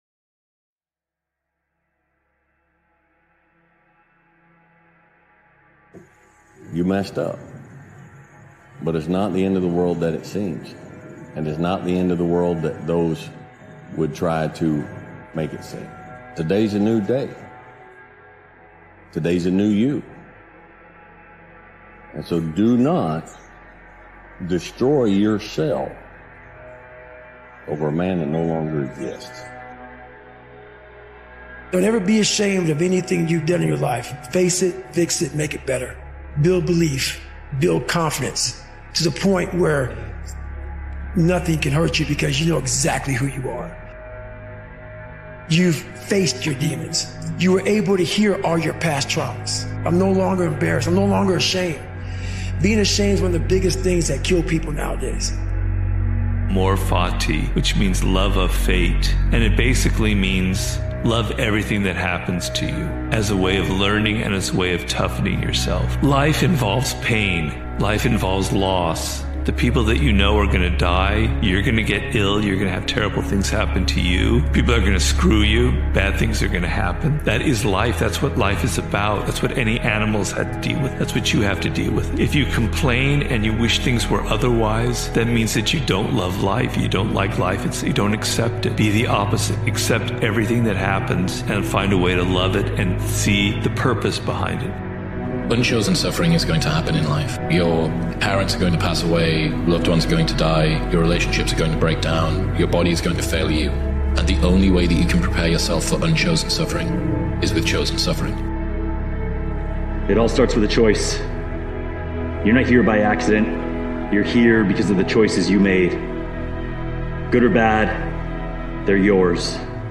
Self-Discovery and Resilience Unleashed - Motivational Speech